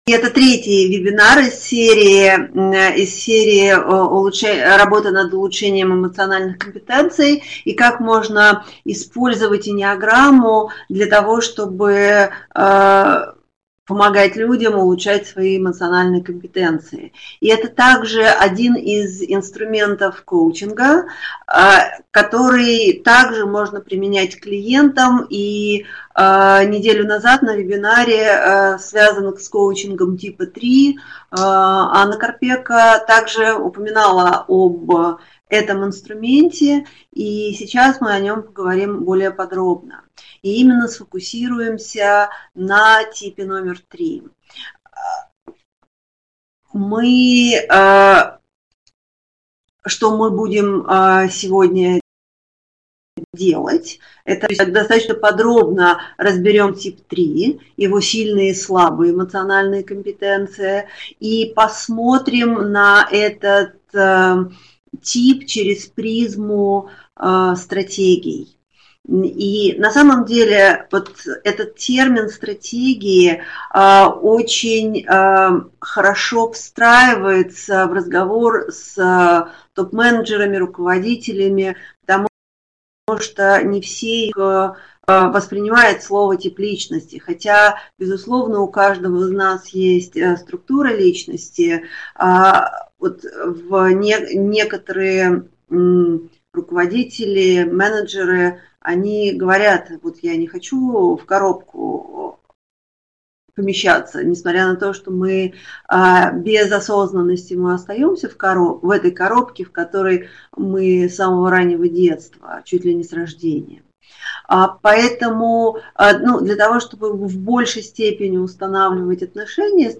Аудиокнига Осознанность в действии. Тип 3 | Библиотека аудиокниг